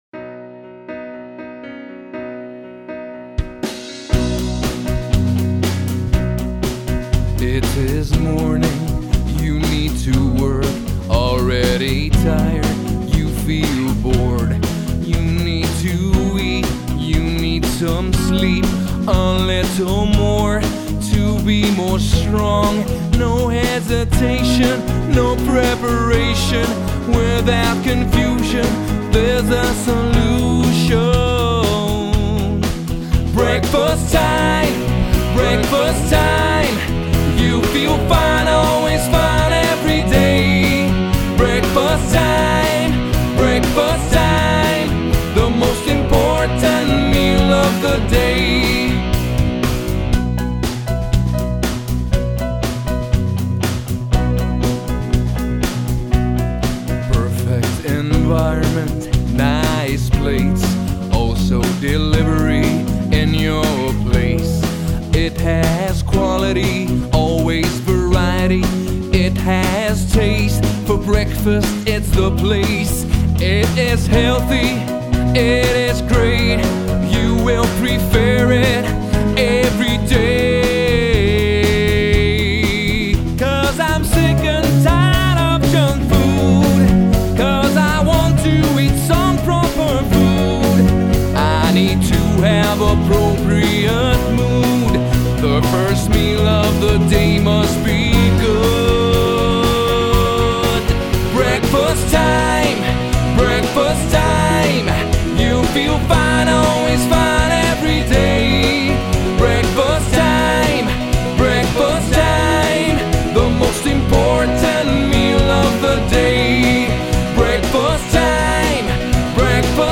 Piano
Vocals